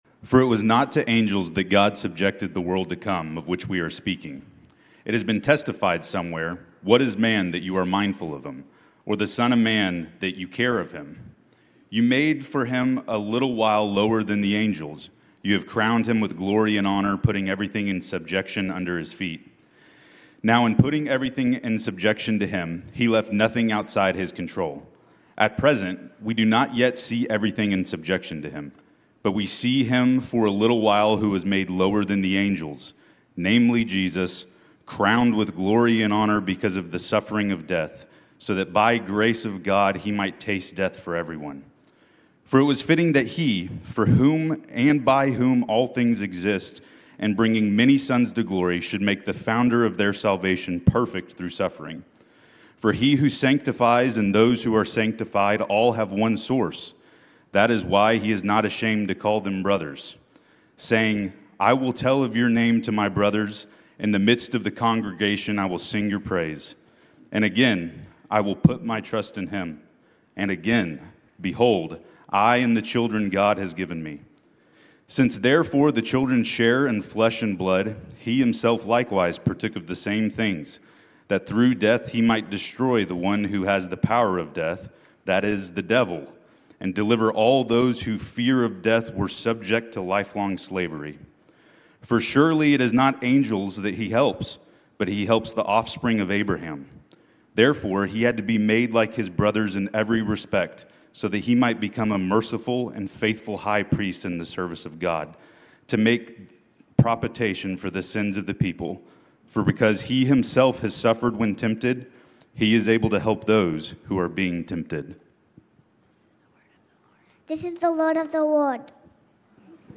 Grace Community Church Lindale Campus Sermons 9_28 Lindale Campus Sep 29 2025 | 00:39:06 1x 00:00 / 00:39:06 Subscribe Share RSS Feed Share Link Embed